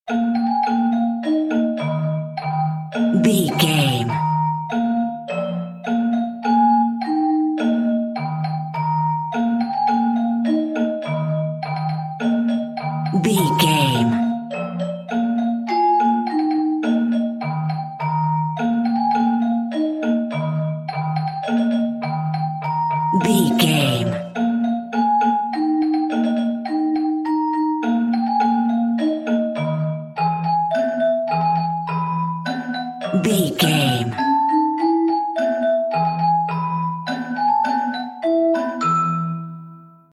Ionian/Major
E♭
nursery rhymes
kids music